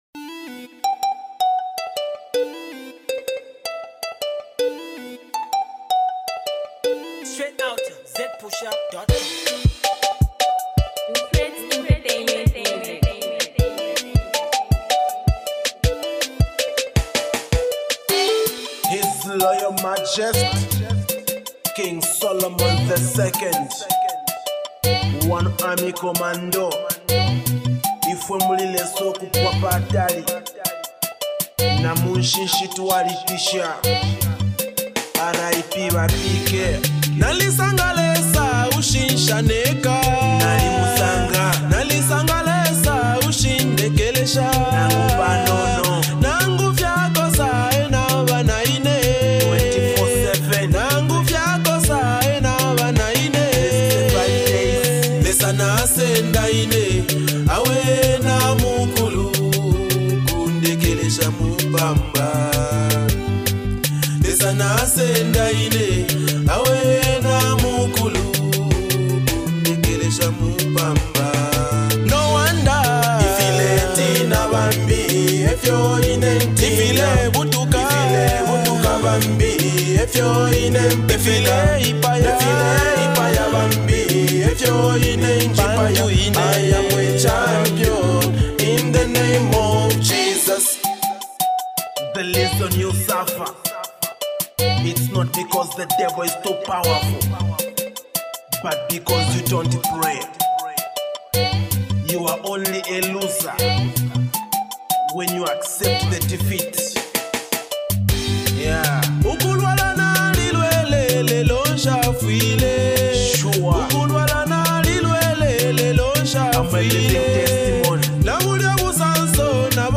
gospel track